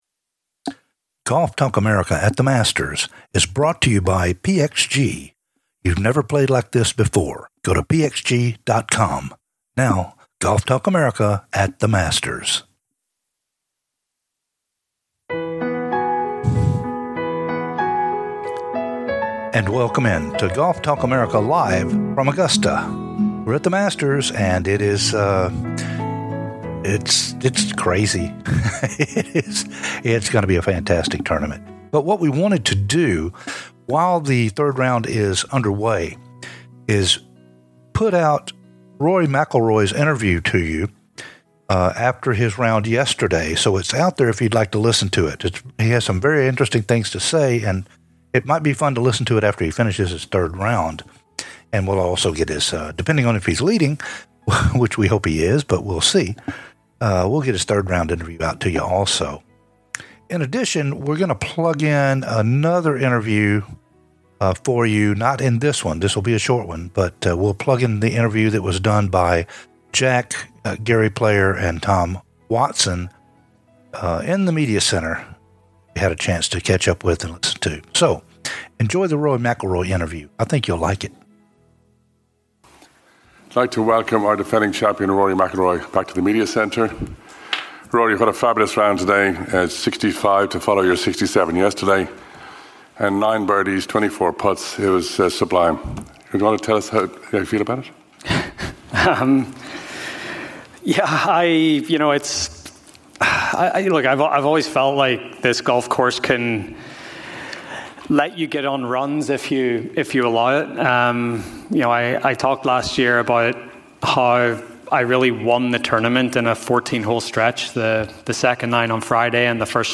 McILROY FRIDAY POST ROUND INTERVIEW "LIVE" FROM THE MASTERS